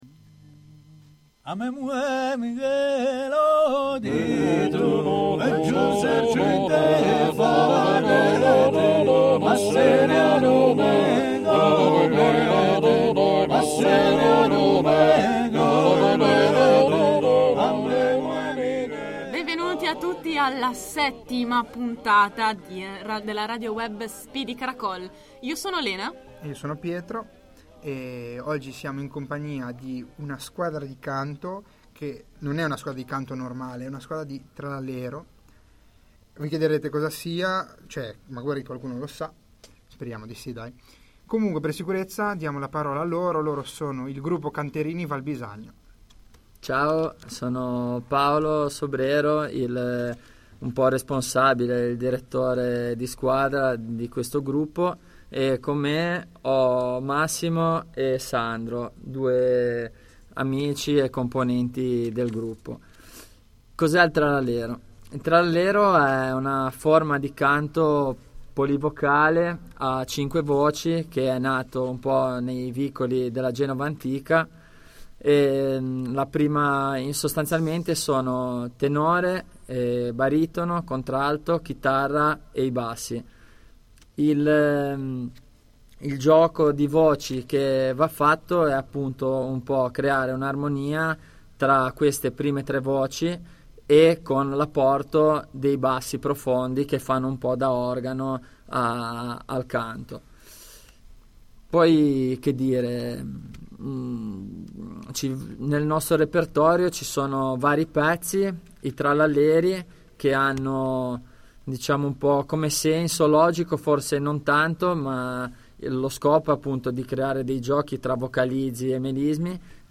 Intervista a Gruppo Canterini Valbisagno